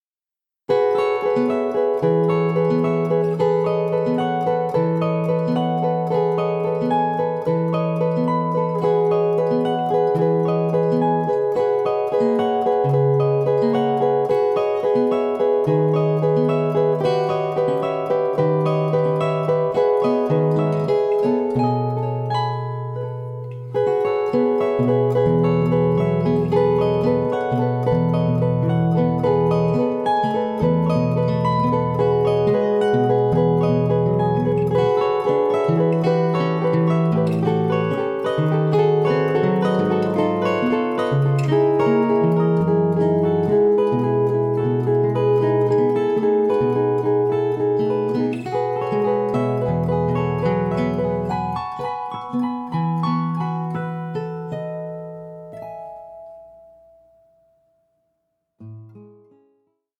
Andantino